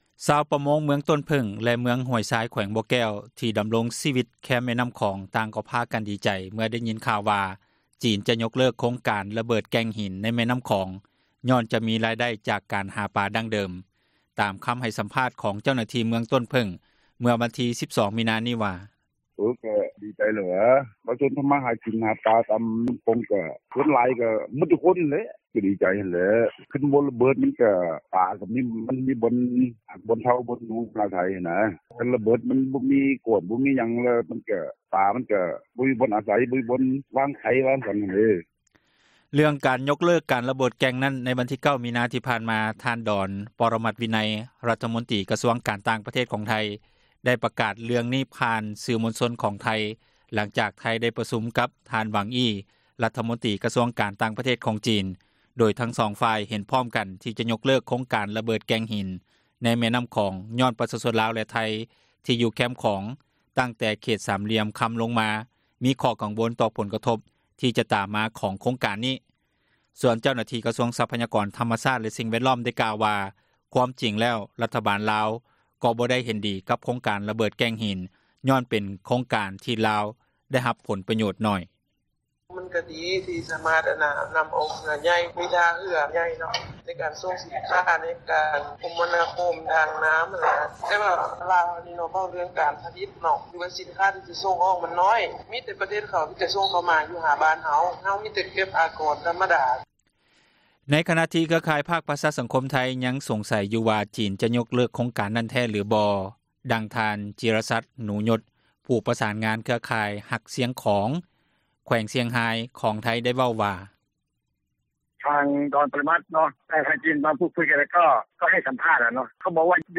ຕາມຄຳໃຫ້ສັມພາດ ຂອງ ເຈົ້າໜ້າທີ່ ເມືອງຕົ້ນເຜີ້ງ ເມື່ອ 12 ມິນາ ນີ້ ວ່າ: